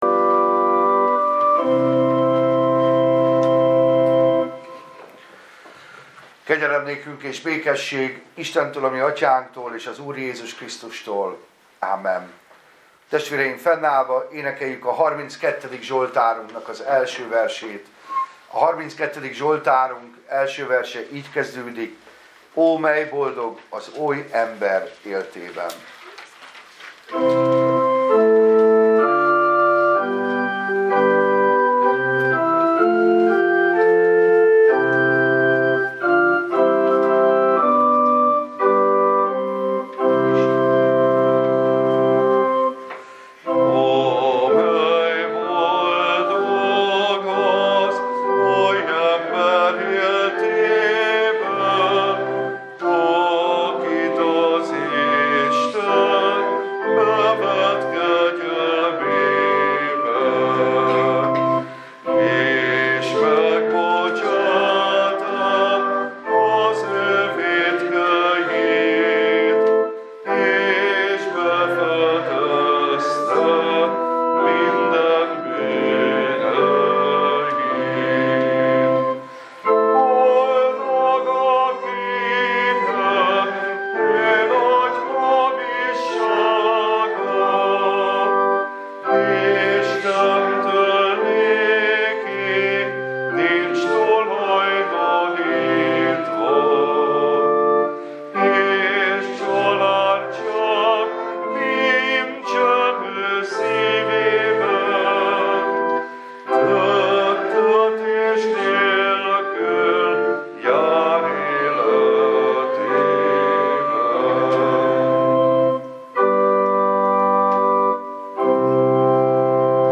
Passage: Lk 19, 41-44 Service Type: Igehirdetés